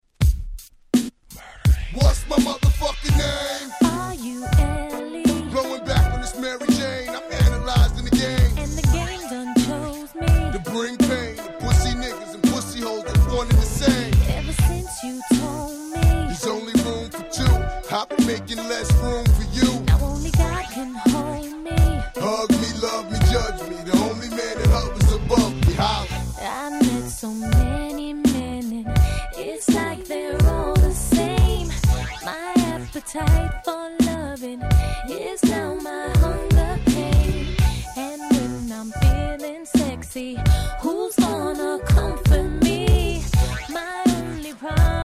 00's R&B Classics !!
めちゃくちゃキャッチーで使えます！